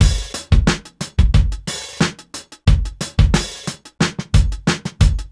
Index of /musicradar/sampled-funk-soul-samples/90bpm/Beats
SSF_DrumsProc1_90-03.wav